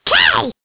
One of Toad's voice clips in Mario Kart DS